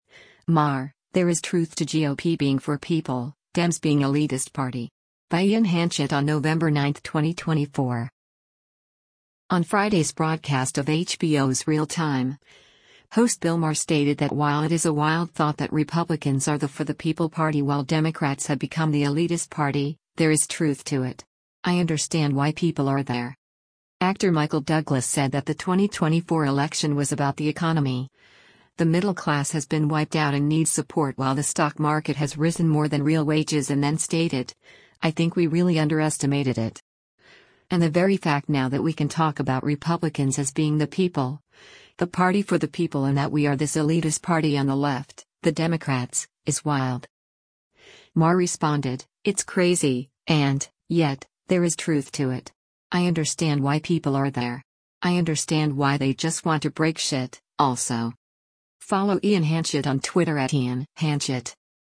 On Friday’s broadcast of HBO’s “Real Time,” host Bill Maher stated that while it is a wild thought that Republicans are the for the people party while Democrats have become the elitist party, “there is truth to it. I understand why people are there.”